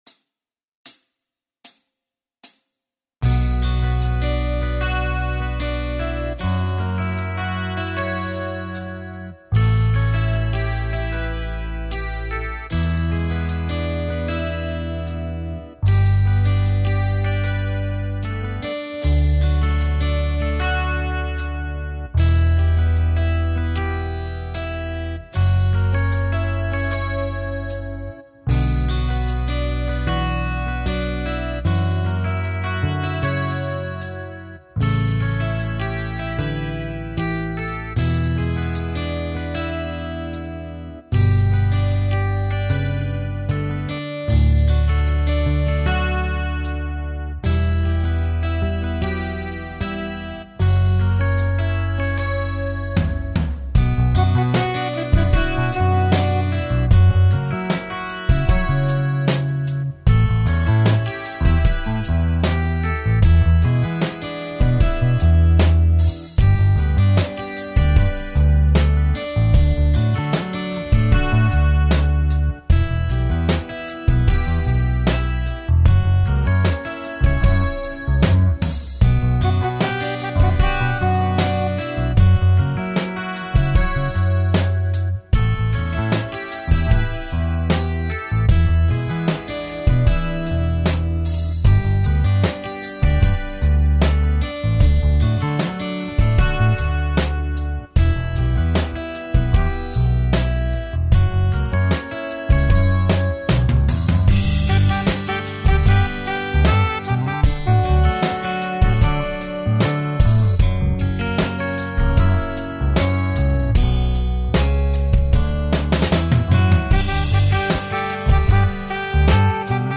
(Bass Guitar)
Lesson Sample